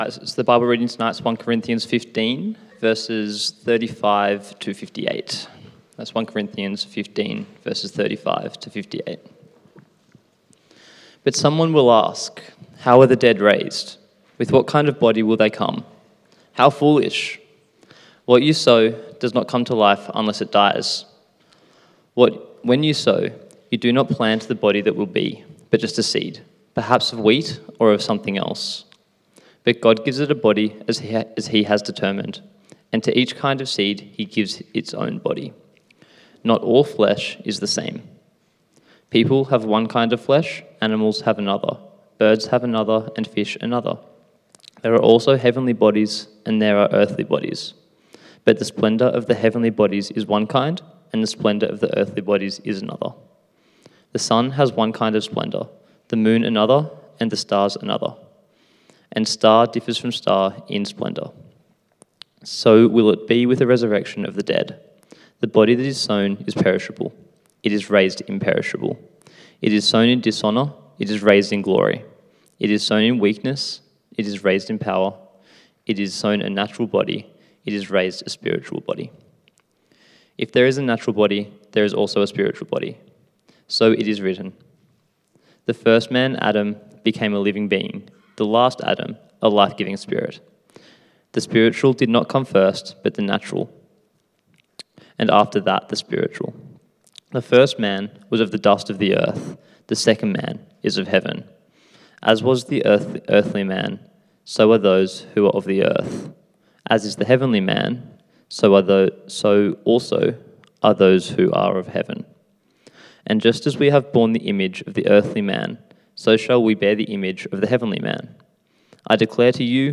Passage: 1 Corinthians 15:35-58 Service Type: 6PM